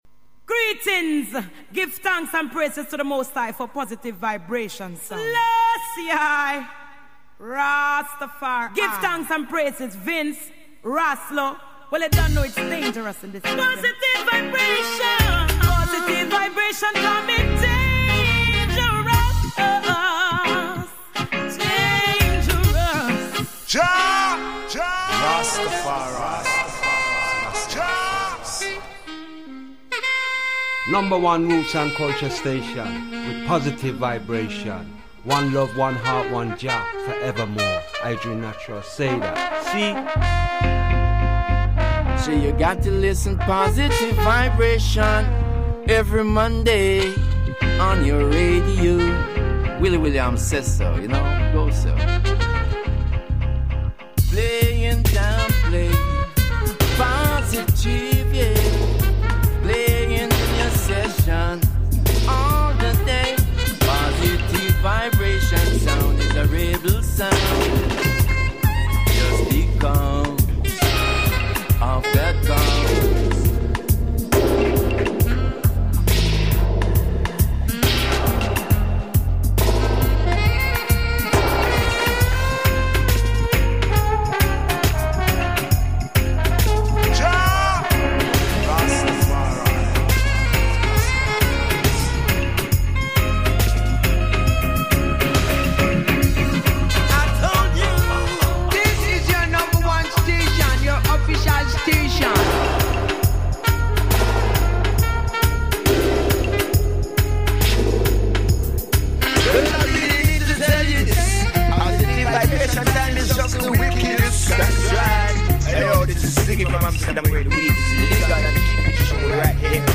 We will do a full interview next time for the part 2...